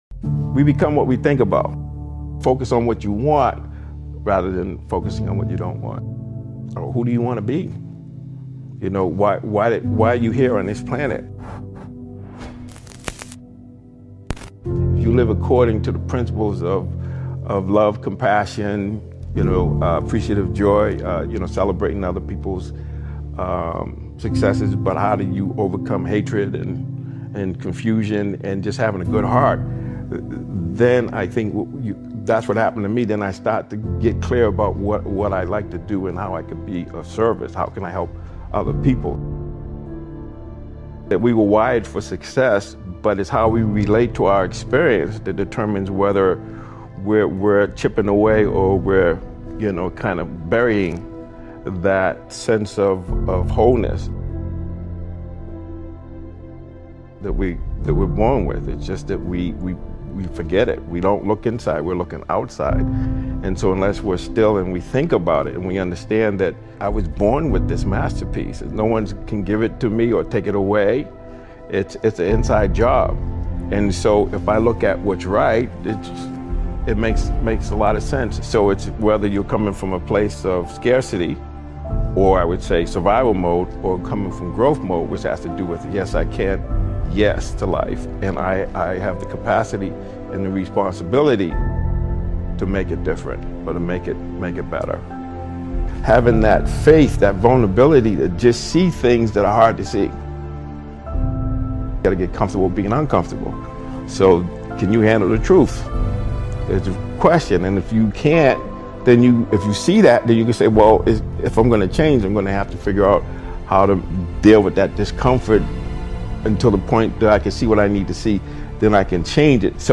Hour-Long Focus Mastery: Curated Speeches to Center on Your Path